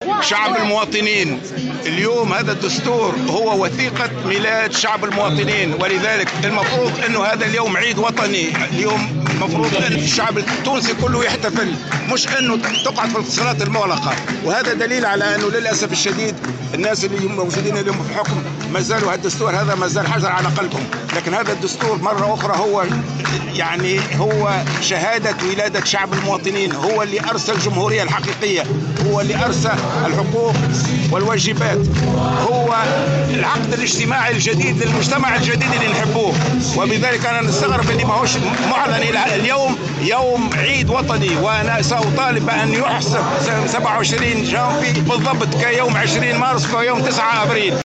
وأضاف المرزوقي في تصريح لمراسل الجوهرة اف ام بصفاقس، خلال اجتماع شعبي لحزب الحراك للاحتفال بالذكرى الخامسة لختم الدستور، أن هذا التاريخ يجب أن يحتفل به الشعب التونسي كعيد الشهداء وعيد الاستقلال.